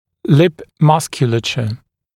[lɪp ‘mʌskjuləʧə][лип ‘маскйулэчэ]мускулатура губ